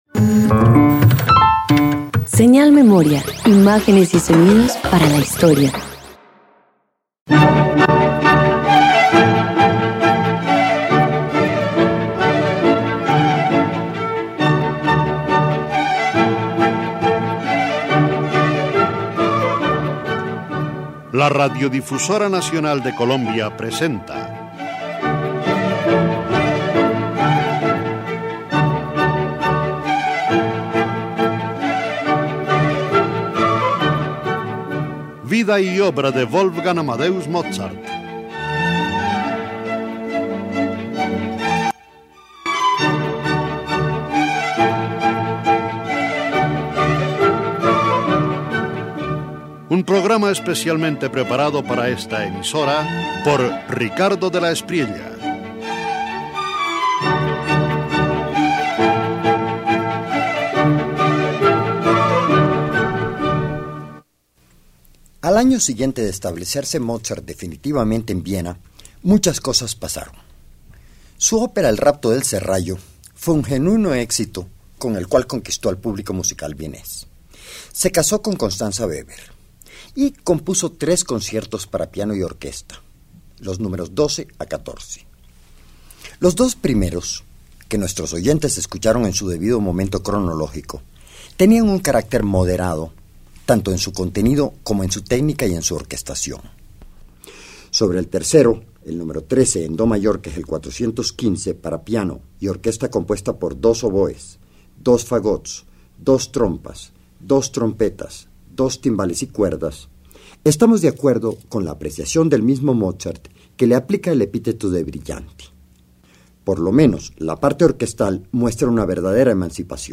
Radio colombiana